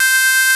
CATOLEADC5.wav